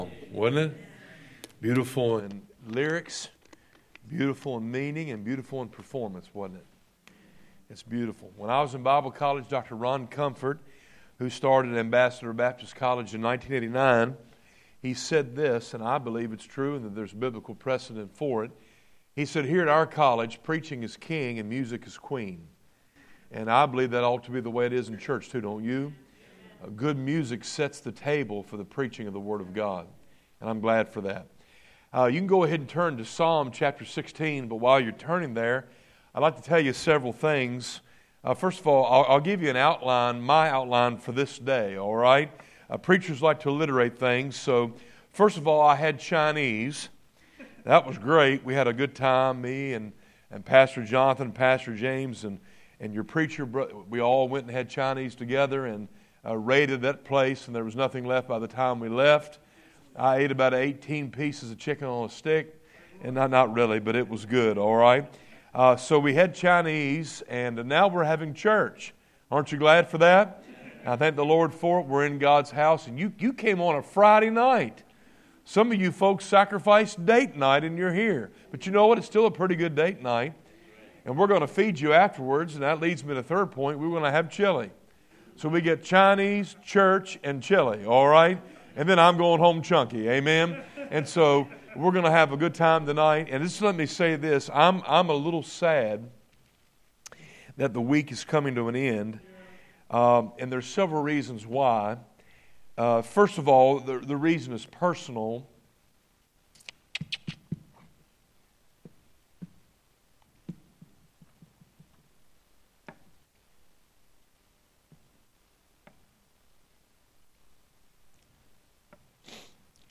2025 Spring Revival Current Sermon
Guest Speaker